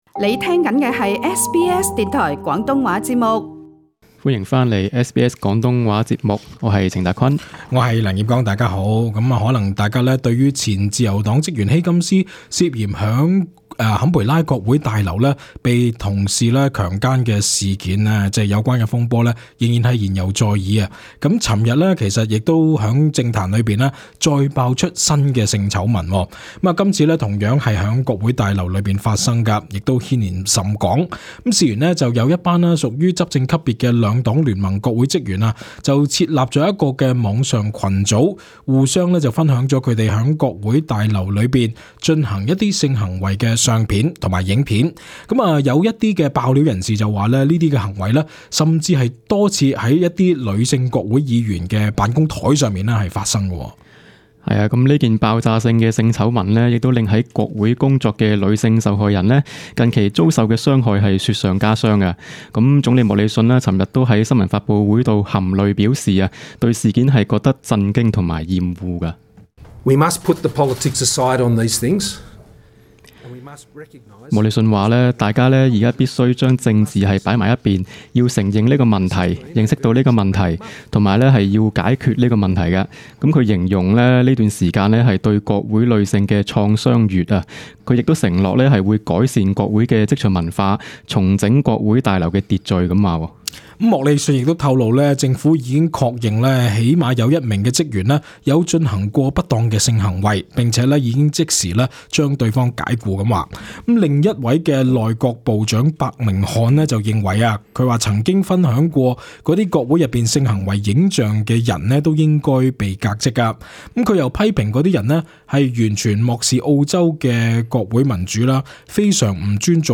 時事報導